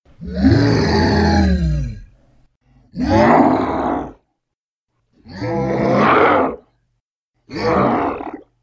Звуки на звонок